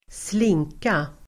Uttal: [²sl'ing:ka]